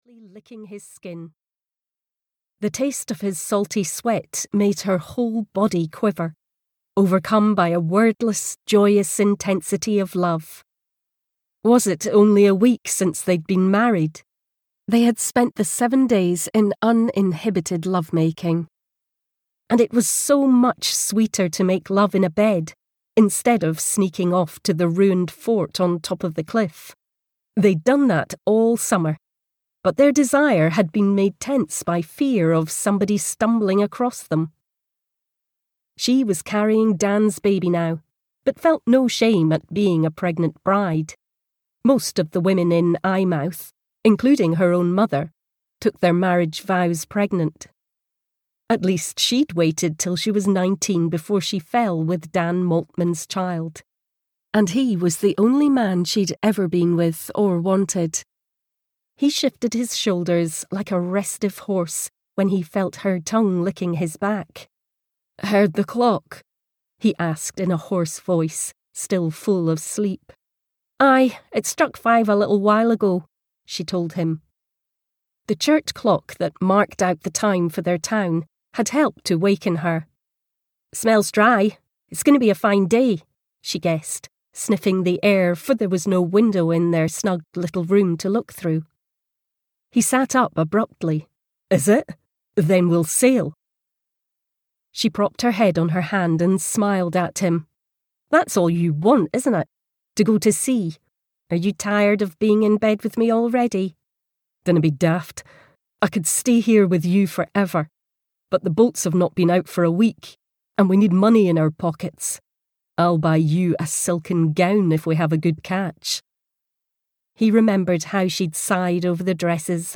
The Storm (EN) audiokniha
Ukázka z knihy